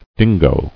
[din·go]